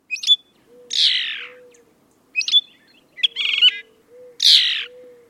kids-hummingbirdaudio.mp3